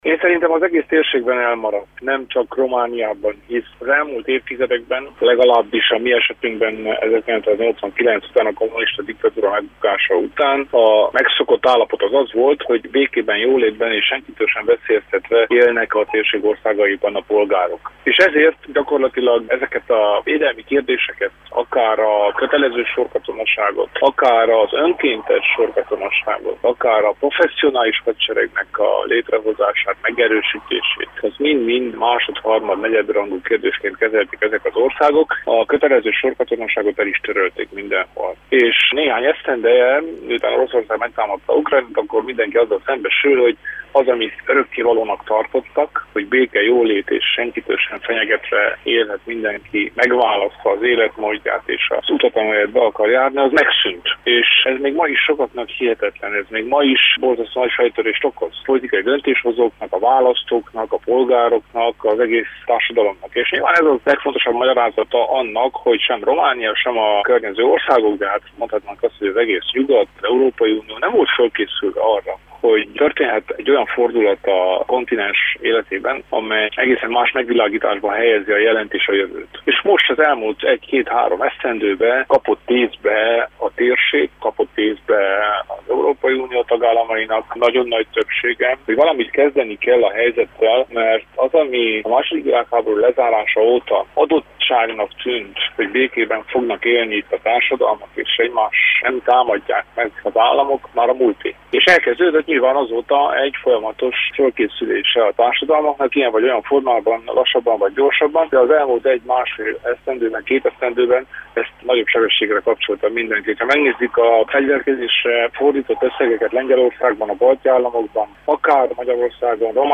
Riporter